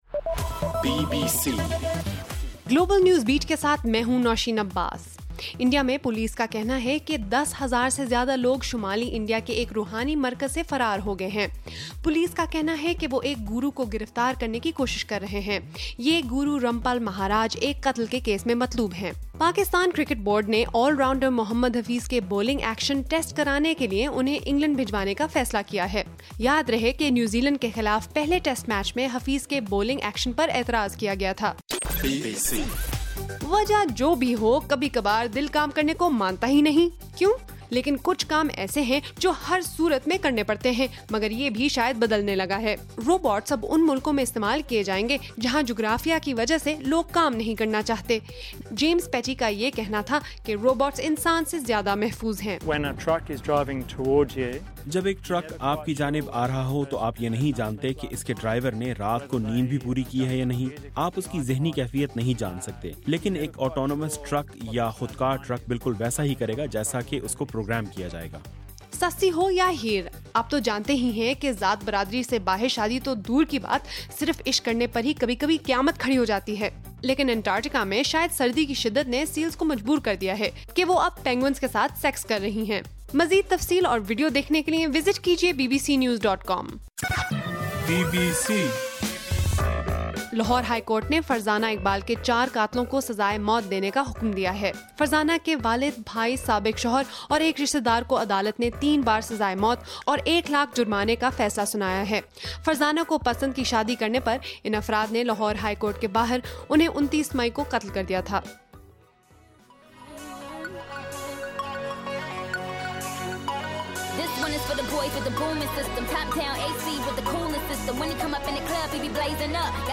نومبر 19: رات 10 بجے کا گلوبل نیوز بیٹ بُلیٹن